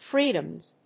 発音記号
• / ˈfridʌmz(米国英語)
• / ˈfri:dʌmz(英国英語)